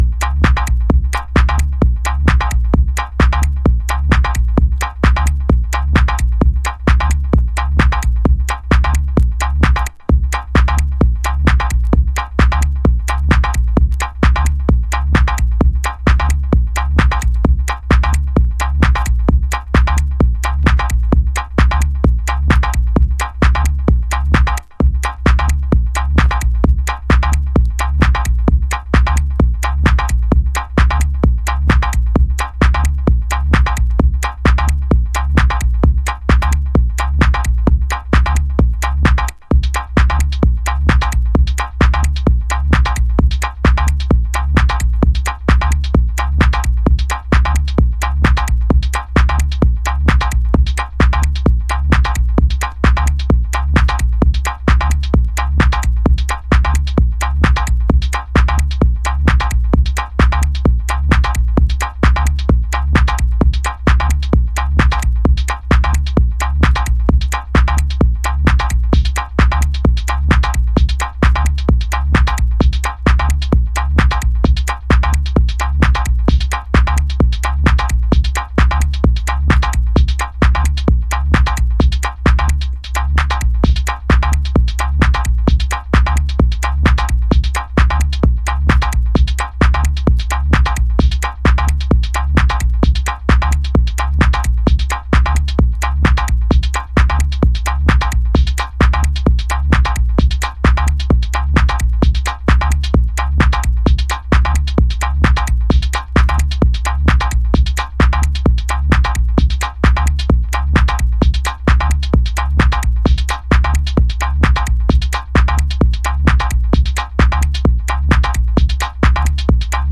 Early House / 90's Techno
BASSIC CHANNELの手法を応用し、装飾を一切省き骨組みだけで構成された彼岸の骨ミニマリズム。